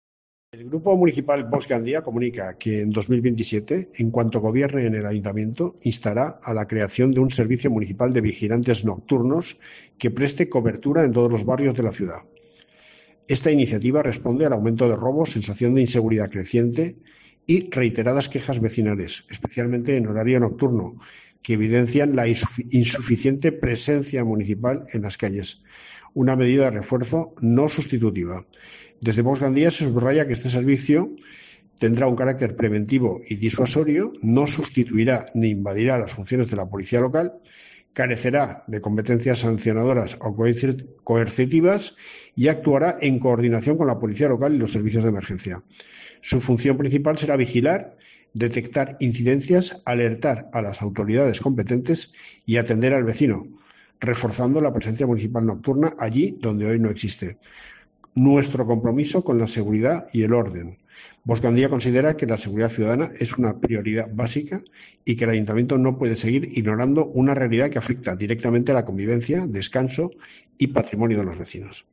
aquí (audio) al concejal Manolo Millet.